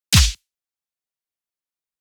Beat.wav